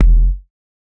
Index of /99Sounds Music Loops/Drum Oneshots/Twilight - Dance Drum Kit/Kicks